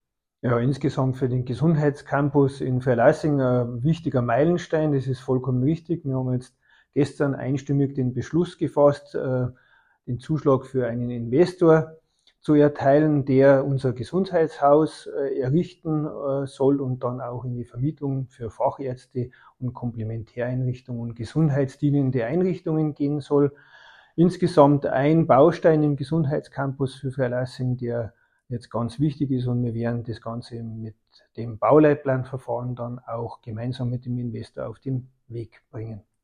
Bürgermeister Markus Hiebl zum „Gesundheitshaus“